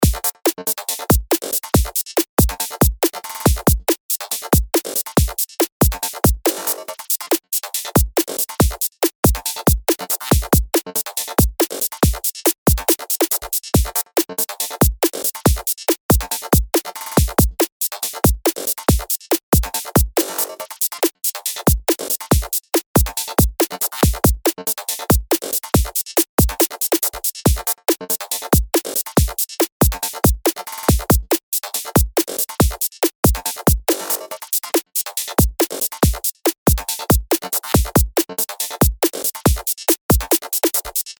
LP 152 – DRUM LOOP – GLITCHY QUIRKY – 140BPM